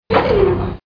Elevator down 2
Category: Sound FX   Right: Personal
Tags: Elevator Sounds Elevator Elevator Sound clips Elevator sound Sound effect